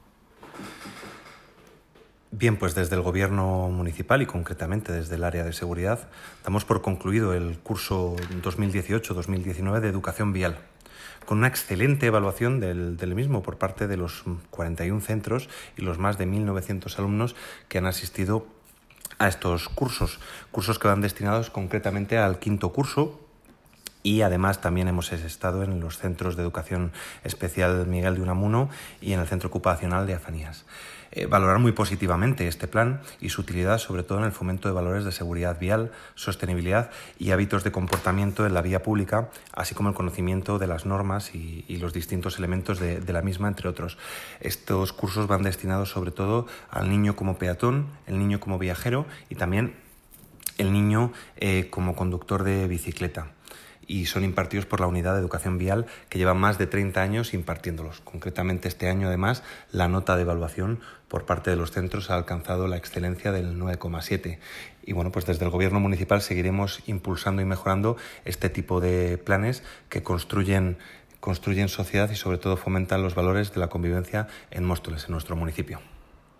Audio - Alejandro Martín (Concejal de Transición Ecológica y Seguridad) Sobre curso educación vial